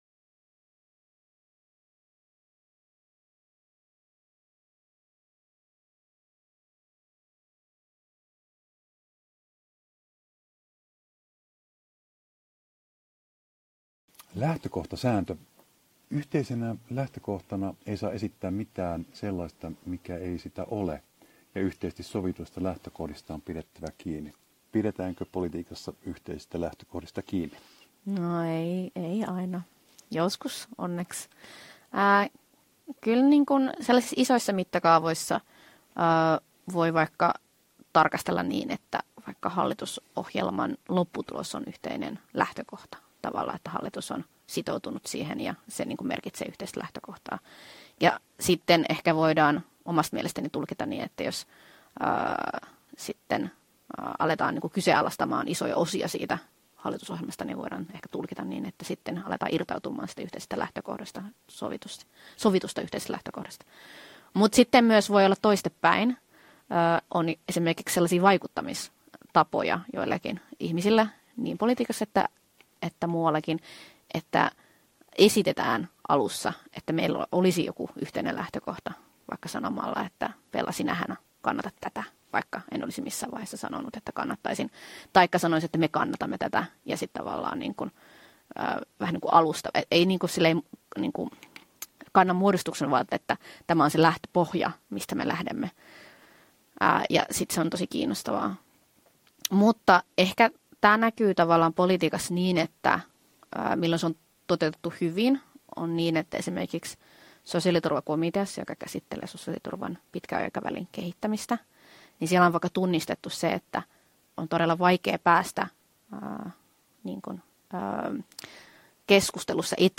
Kansanedustaja Forsgren haastattelu, osa 3 (korjattu tiedosto)